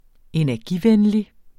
Udtale [ enæɐ̯ˈgi- ]